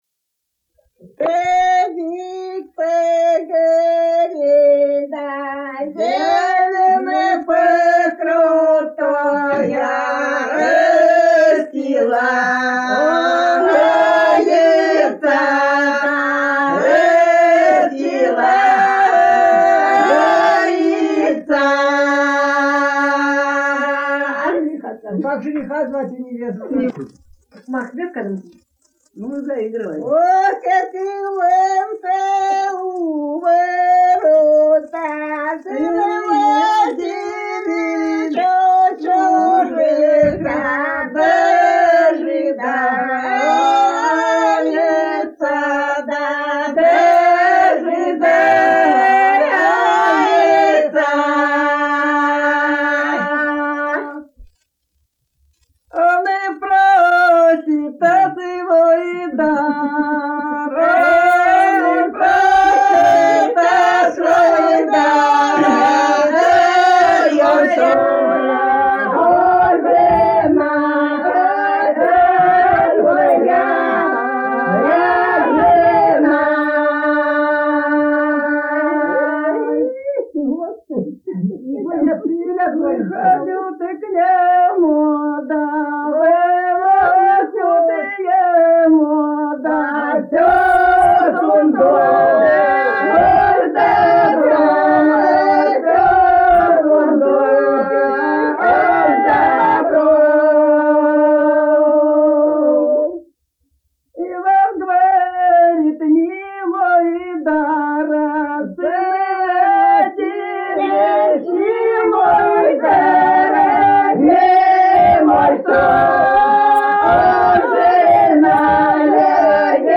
Народные песни Касимовского района Рязанской области «Садик по горе», свадебная.